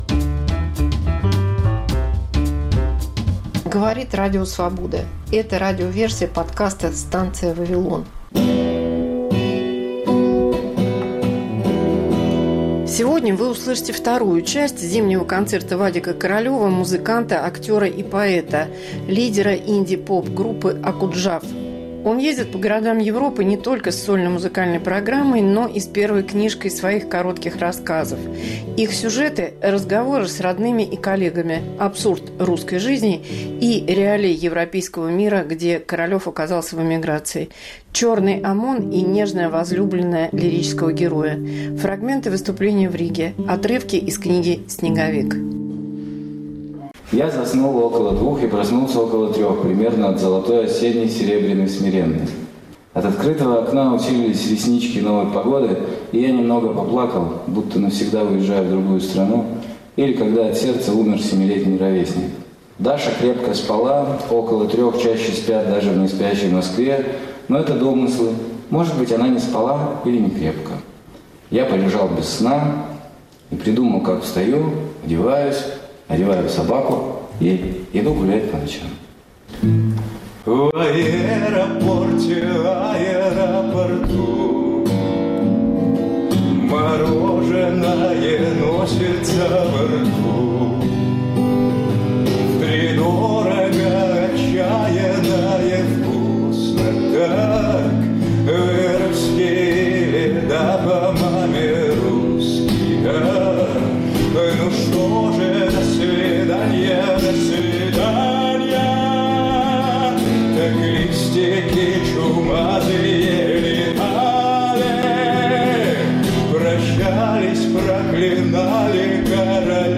Концерт